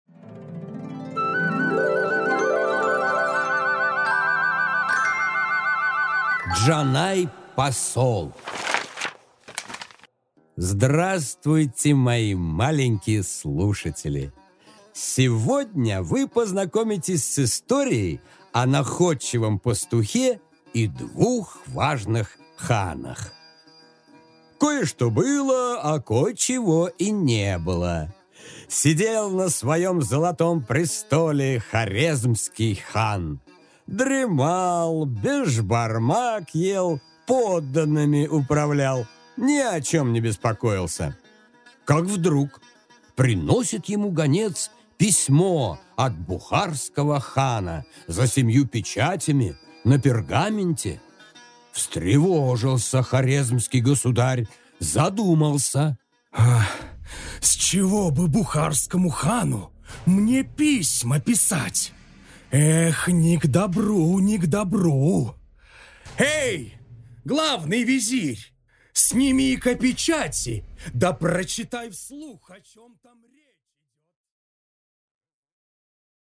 Аудиокнига Джанай-посол